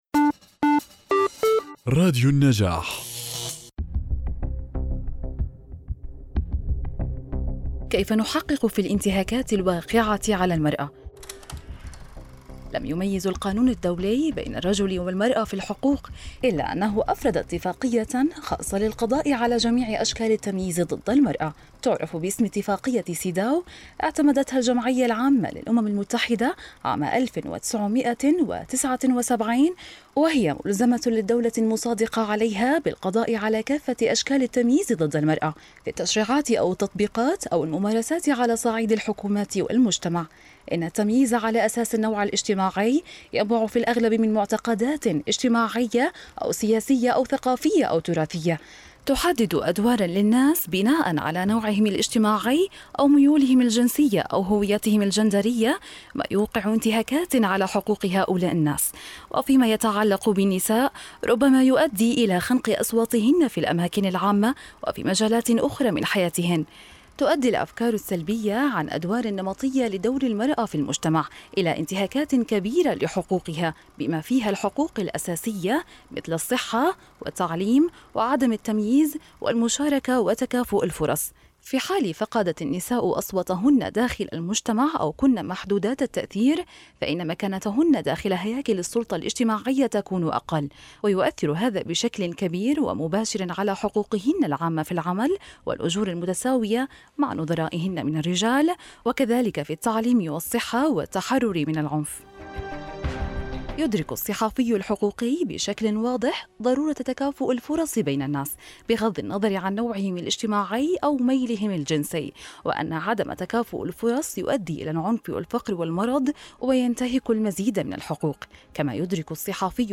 الكتاب المسموع